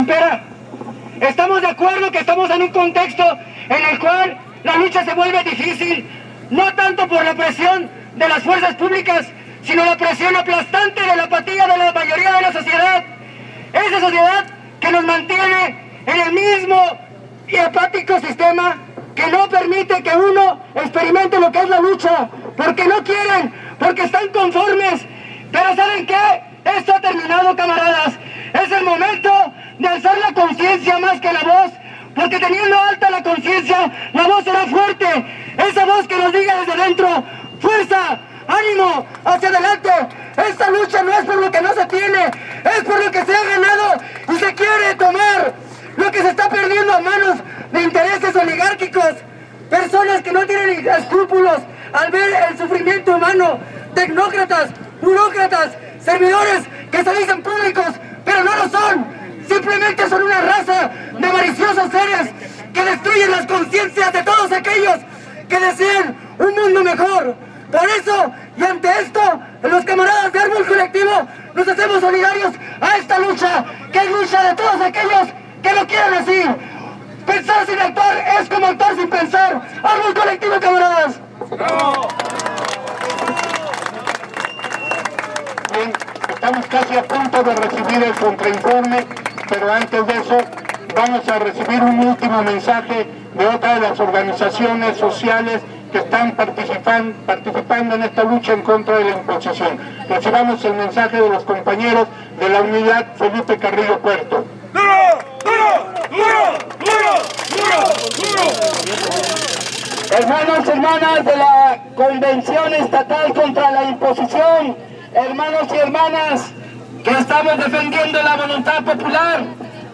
Discursos en manifestación